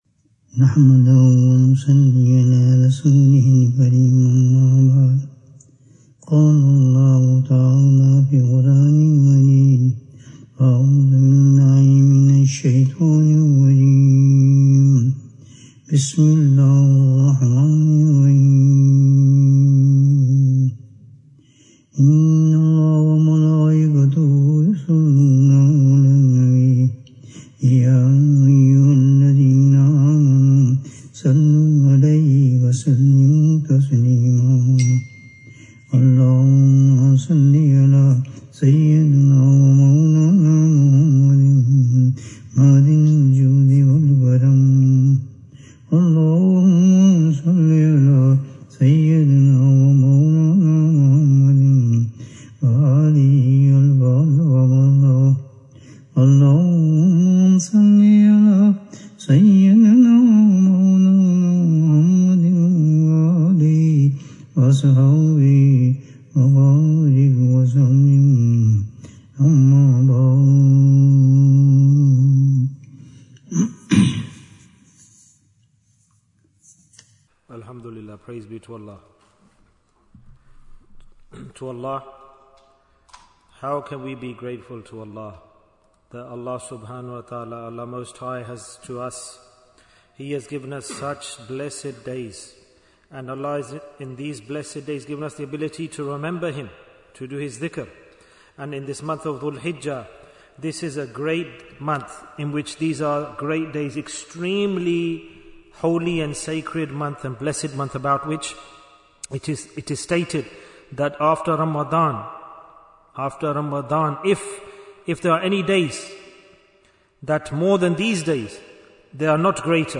What Deeds Should be Done in the Asharah of Dhul-Hijjah? Bayan, 23 minutes29th May, 2025